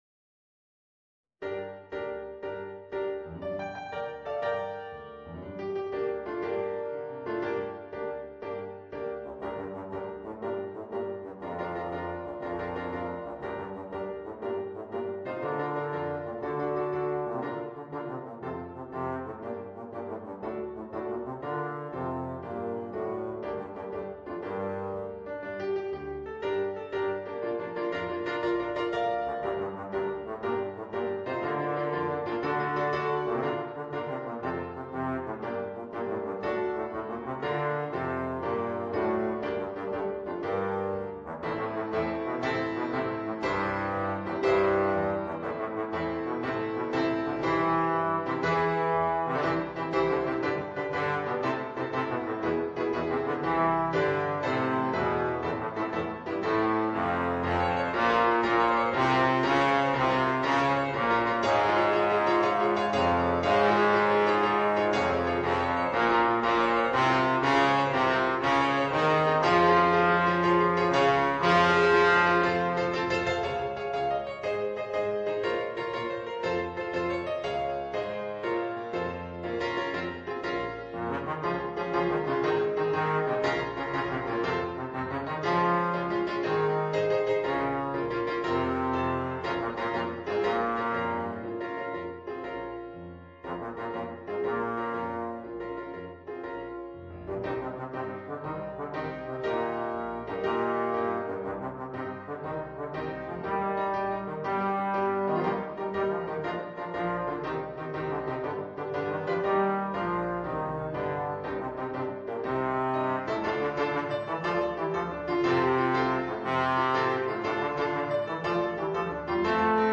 Voicing: Bass Trombone and Piano